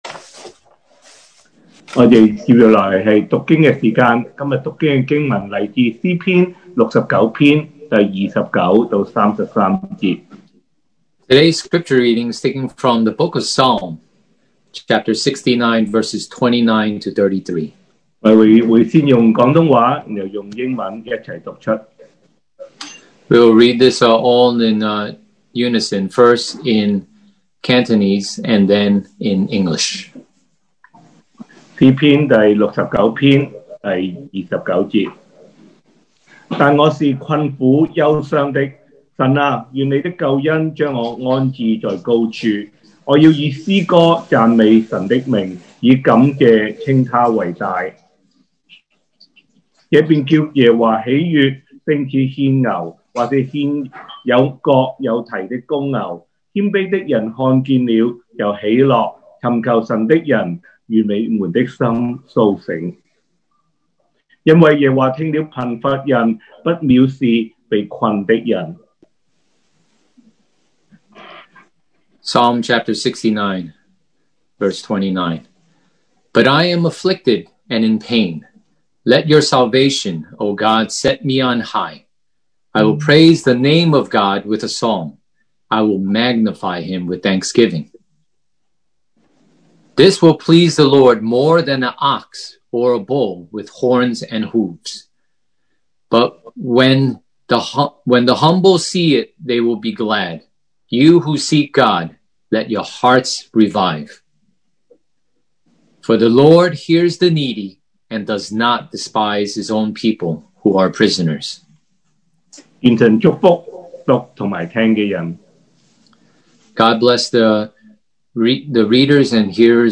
sermon audios
Service Type: Thanksgiving Day